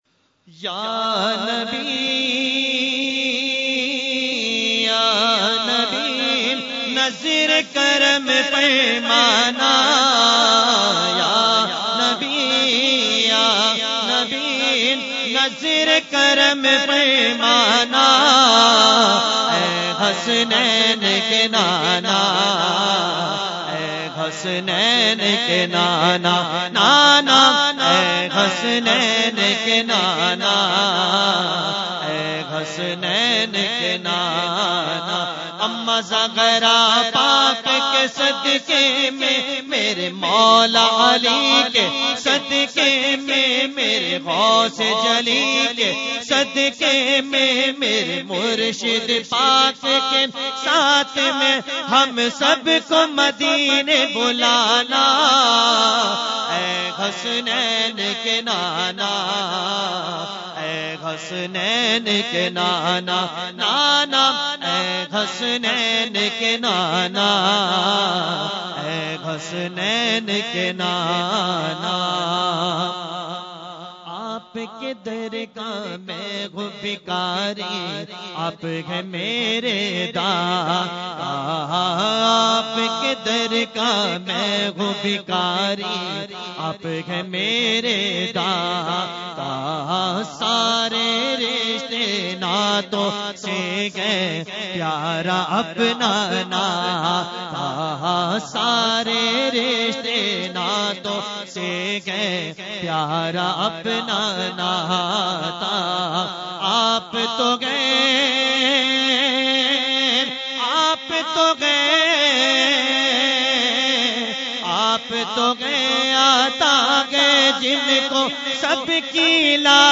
Category : Naat | Language : UrduEvent : Urs Ashraful Mashaikh 2018